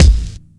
Tuned kick drum samples Free sound effects and audio clips
• Crisp Kickdrum Sample A Key 123.wav
Royality free kick drum single hit tuned to the A note. Loudest frequency: 707Hz
crisp-kickdrum-sample-a-key-123-e88.wav